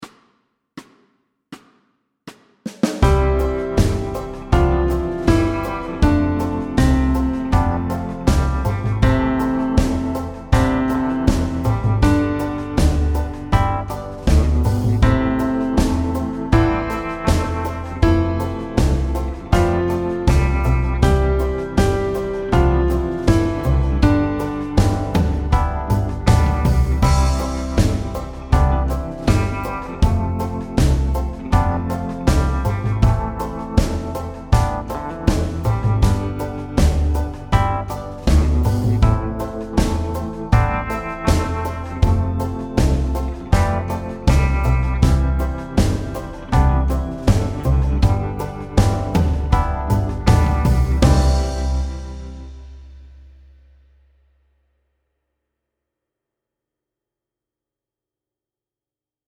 Fast C instr (demo)